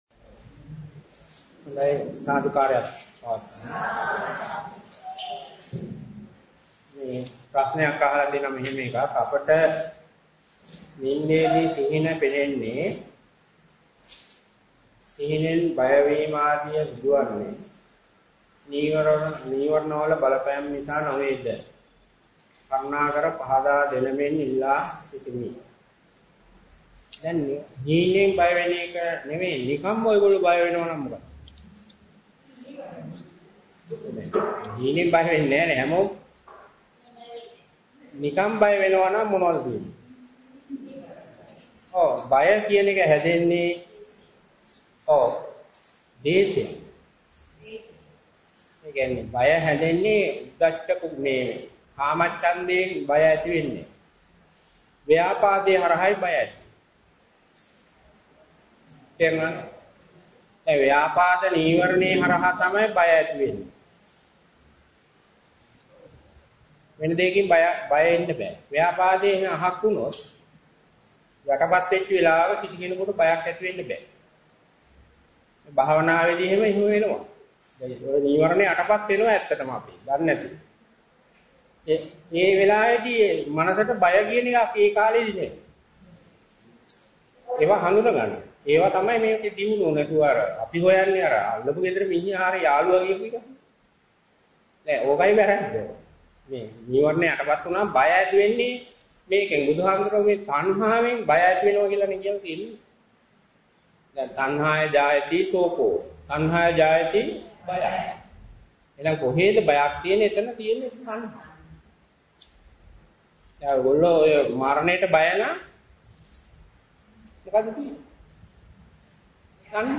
We are very pleased to present the following conducted at Malabe in 2016. We have enhanced the sound quality through audio editing.